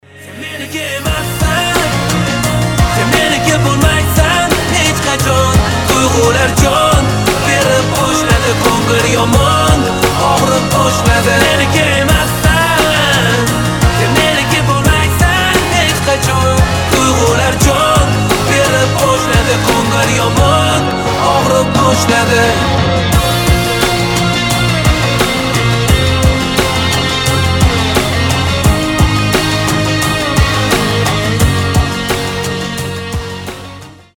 • Качество: 320, Stereo
узбекские